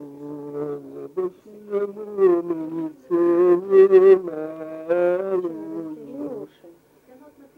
Мягкое цоканье (совпадение литературных аффрикат /ц/ и /ч’/ в мягком /ц’/)
/ту”т св’е-рнуу”-лсэ ваа-с’и”-лйу-шко в’и”-дно на-кон”аа ско-ц’и”л/